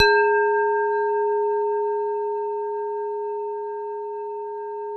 WHINE  G#2-R.wav